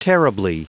Prononciation du mot terribly en anglais (fichier audio)
Prononciation du mot : terribly